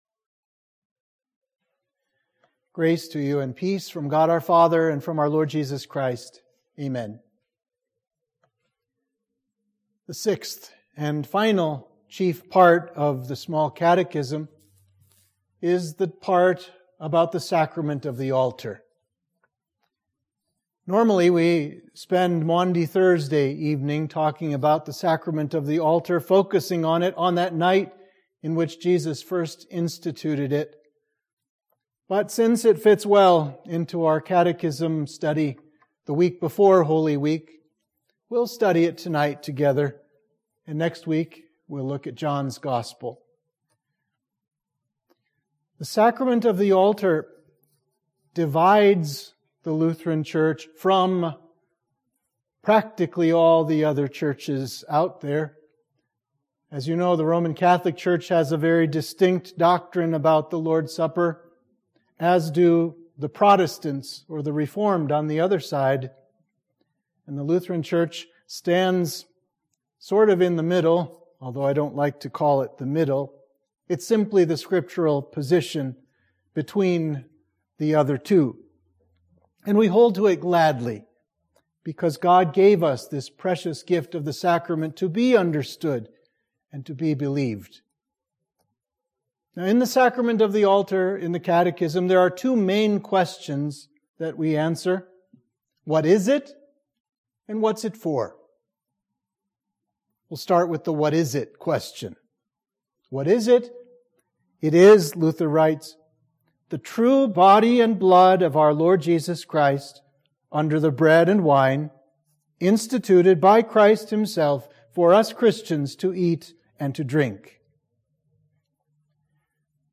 Sermons on the Small Catechism: The Lord’s Supper